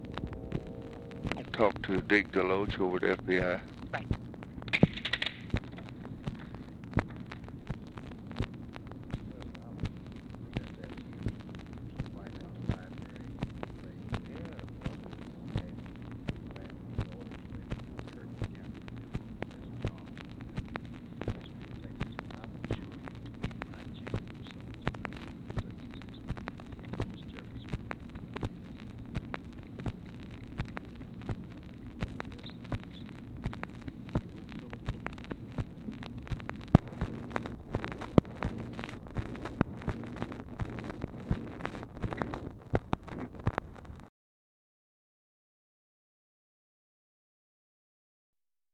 LBJ ASKS OPERATOR TO PLACE CALL TO CARTHA "DEKE" DELOACH AT FBI; FAINT OFFICE CONVERSATION WHILE LBJ ON HOLD
Conversation with TELEPHONE OPERATOR and OFFICE CONVERSATION
Secret White House Tapes